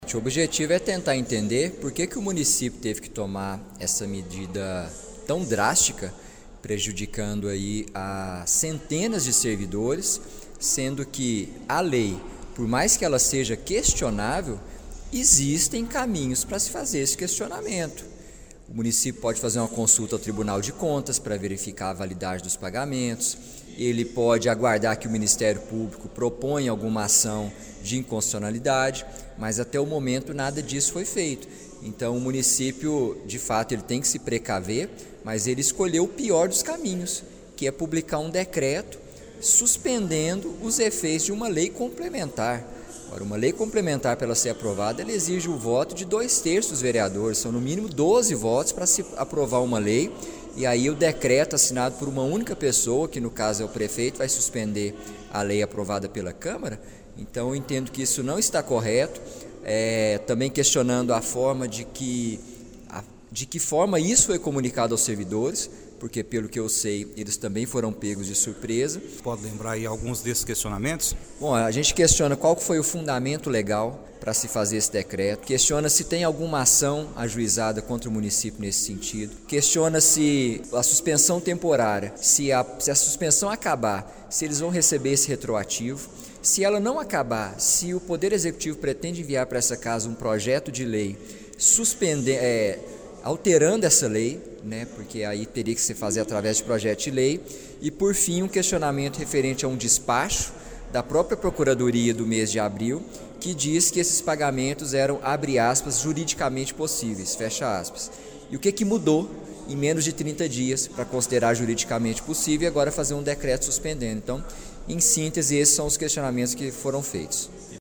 Durante a reunião da Câmara Municipal de Pará de Minas, realizada ontem (27), o vereador Gustavo Henrique Duarte Silva (PSDB) manifestou forte crítica ao decreto da prefeitura que suspende temporariamente pagamentos de direitos dos Agentes Comunitários de Saúde e dos Agentes de Combate às Endemias.